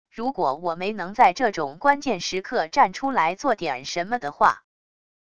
如果我没能在这种关键时刻站出来做点什么的话wav音频生成系统WAV Audio Player